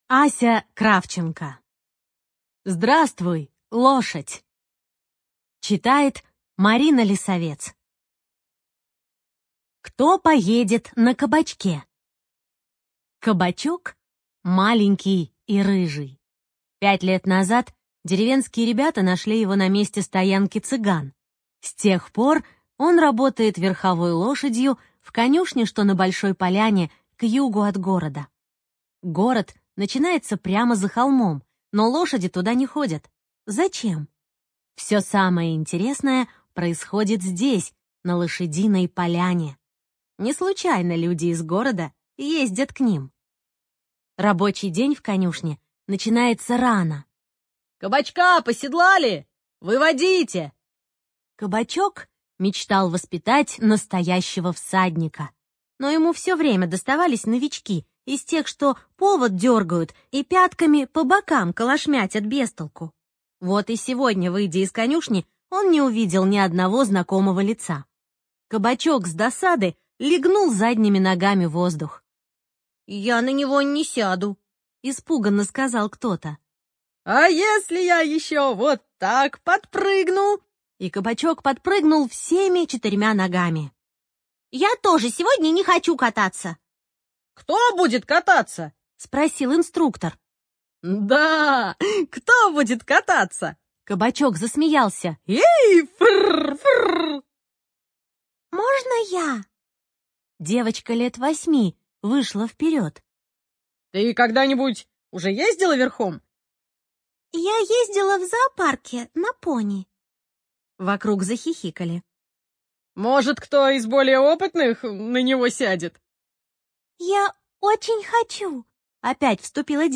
ЖанрПриключения, Детская литература